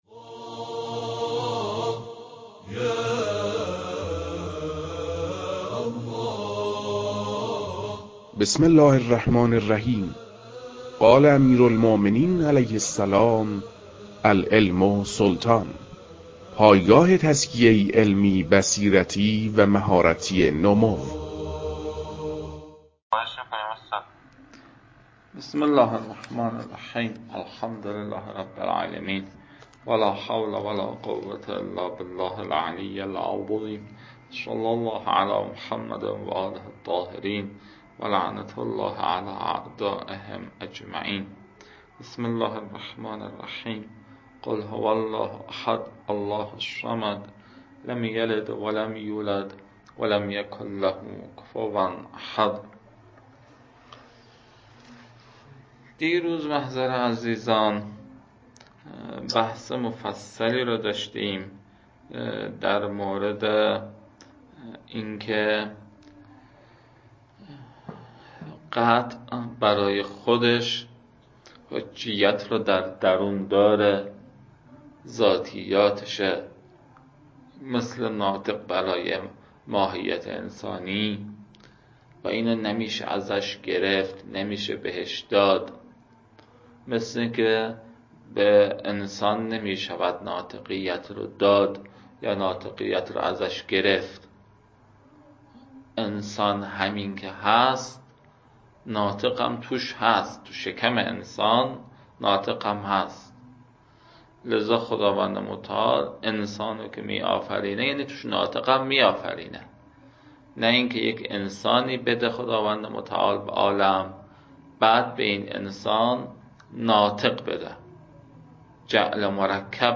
فایل های مربوط به تدریس مبحث رسالة في القطع از كتاب فرائد الاصول متعلق به شیخ اعظم انصاری رحمه الله